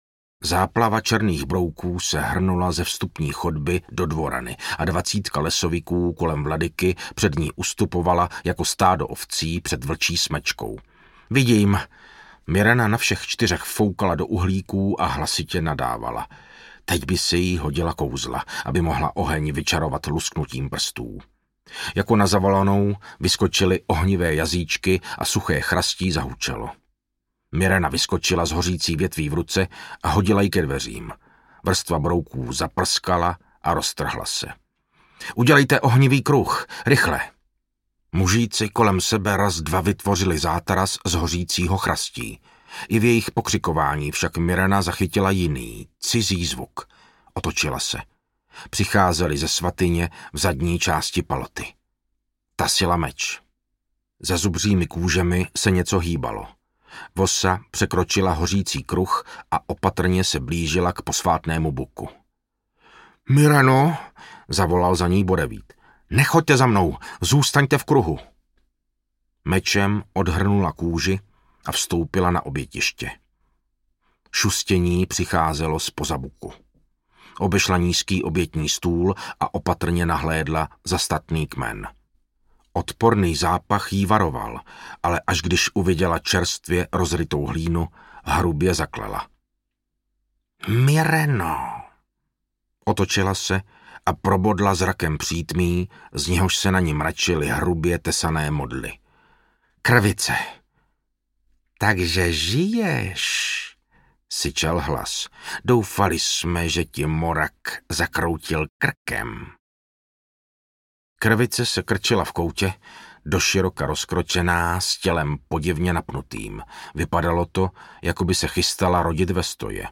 Ukázka z knihy
Čte Martin Finger.
Vyrobilo studio Soundguru.
• InterpretMartin Finger